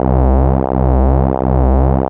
SYNTH BASS-2 0005.wav